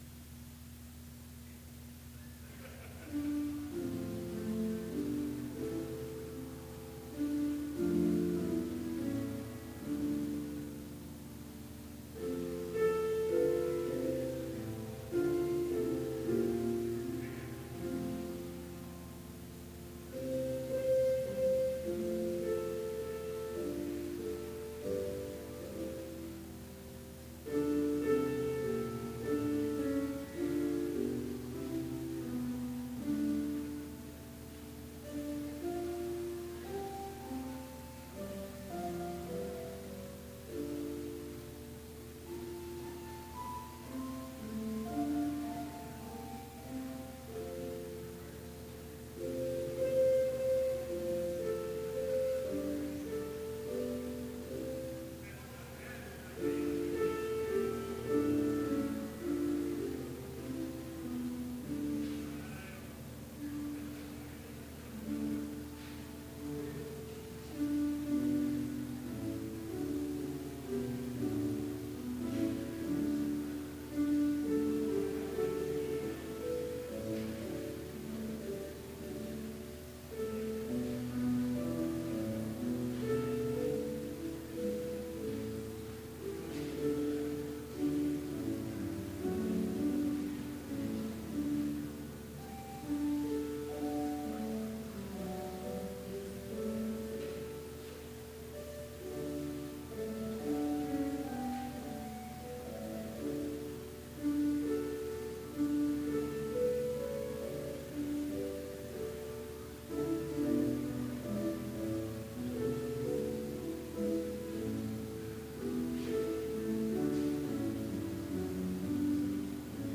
Sermon audio for Advent Vespers - December 9, 2015